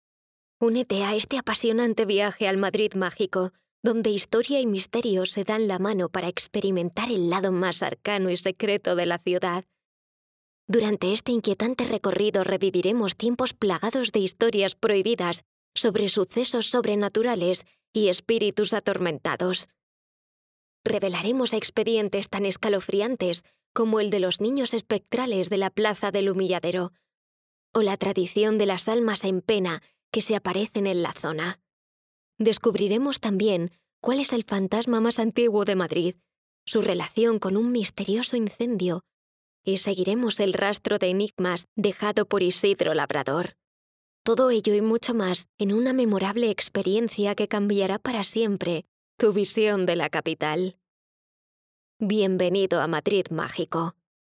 Young, Urban, Cool, Reliable, Natural
Audio guide